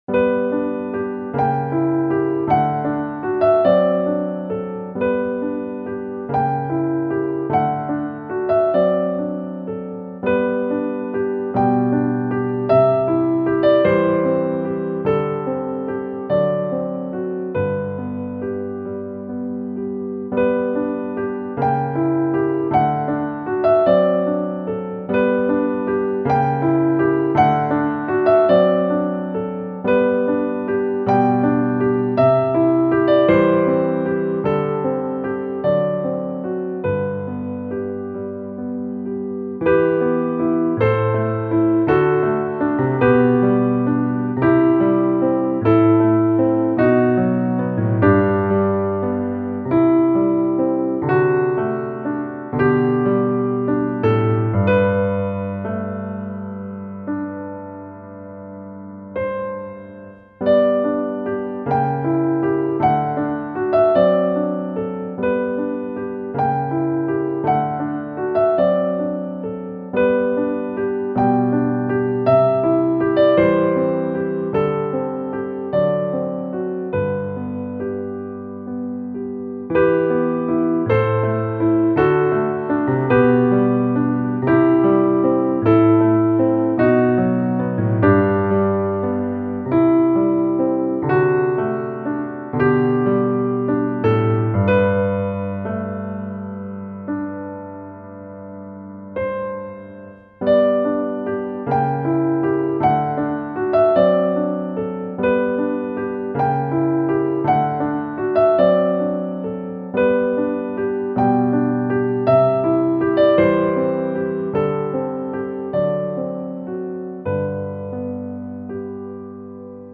♪サウンドプログラマ制作の高品質クラシックピアノ。